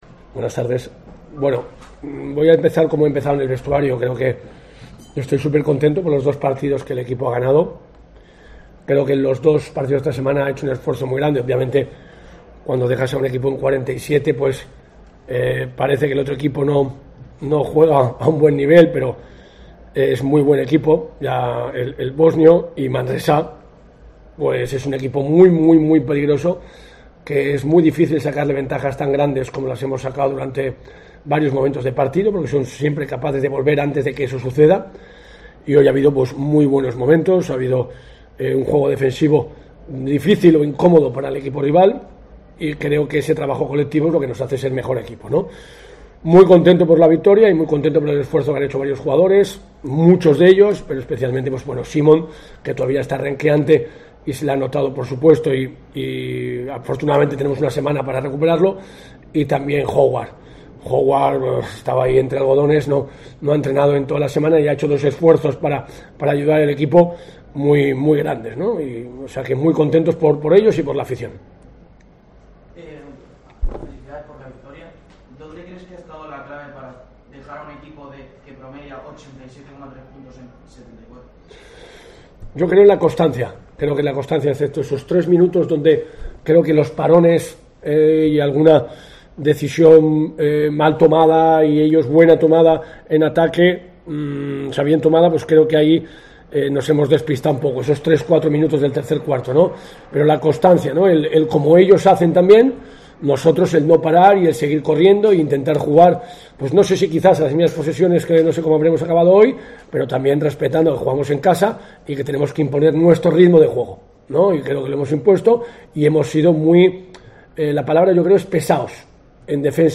Estoy súper contento por el partido que hicimos y el trabajo realizado ante un rival como el Baxi Manresa que es muy peligroso y al que es muy difícil sacarle las ventajas que nosotros tuvimos", comenzó diciendo en rueda de prensa.